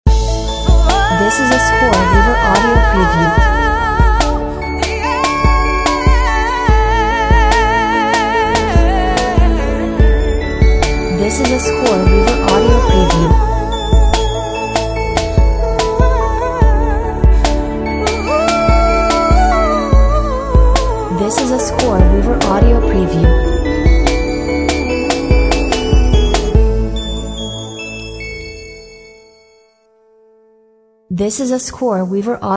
Touching vocal RnB Cue for highly emotional moments!